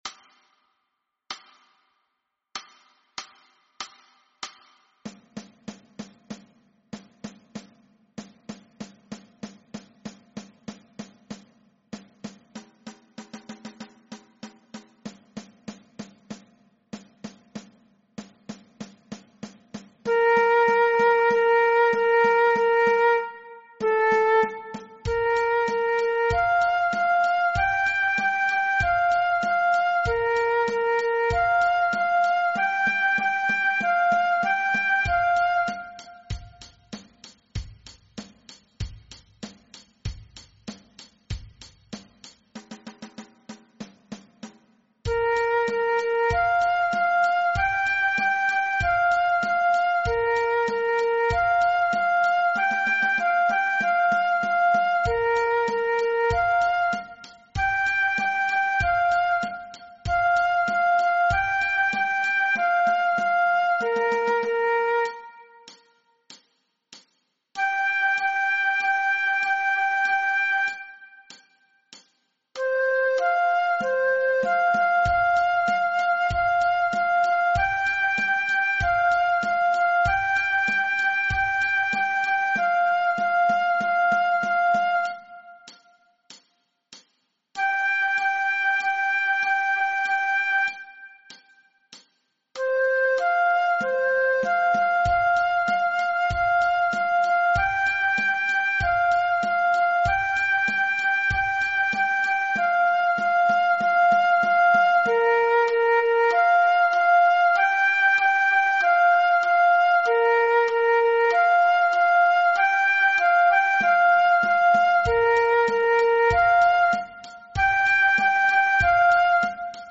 Aangepaste oefening NT 6.0 Dwarsfluit
Dwarsfluit aangepast